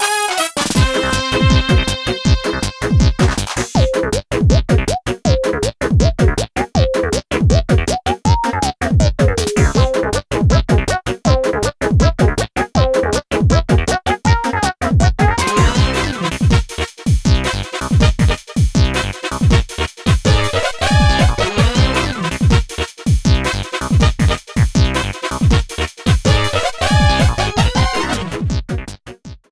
Game rip
Fair use music sample